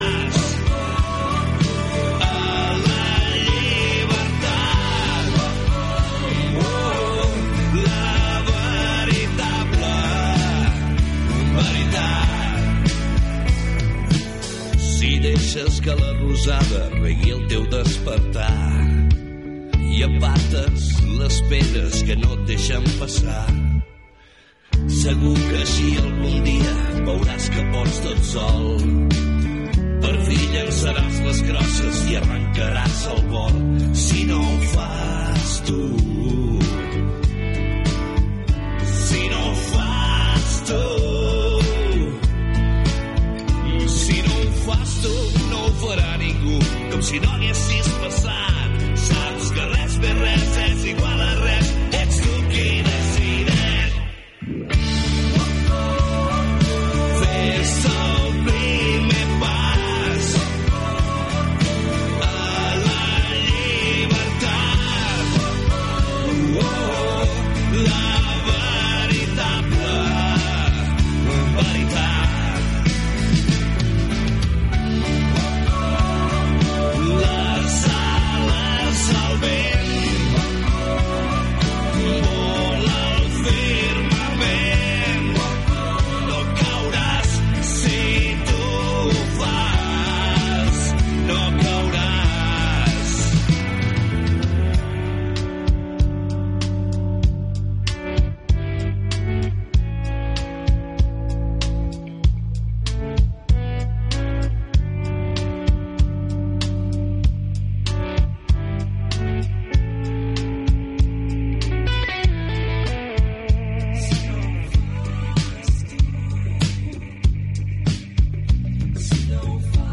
1 Borinot Borinot del 28/2/2025 1:00:00 Play Pause 2d ago 1:00:00 Play Pause נגן מאוחר יותר נגן מאוחר יותר רשימות לייק אהבתי 1:00:00 "Borinot Borinot" és un programa musical que es centra en la música ska i el reggae pero on també sona oi, punk i hardcore. A banda de la música també hi ha entrevistes, agenda i una mica d'humor d'anar per casa... stay tuned!!!